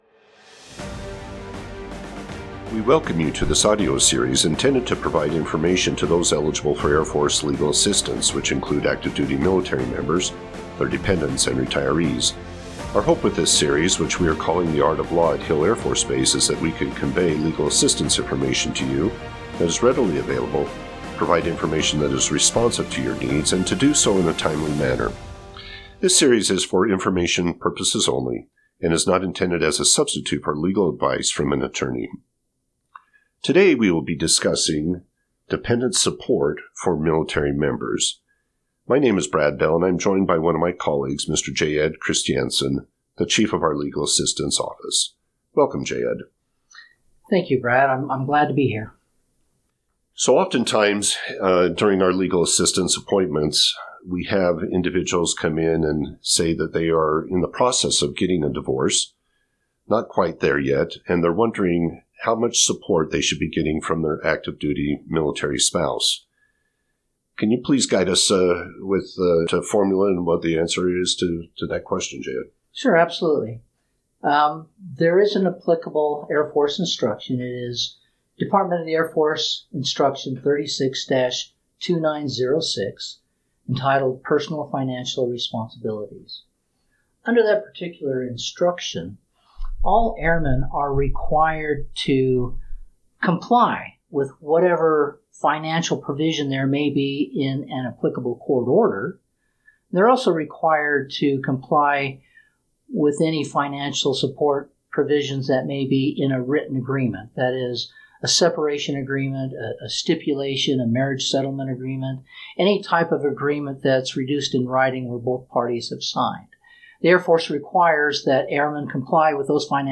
In this episode of the Art of Law, we take a dive into dependent support for family members. We are joined by two JAG attorneys to answer common questions about support to family members during and after a divorce.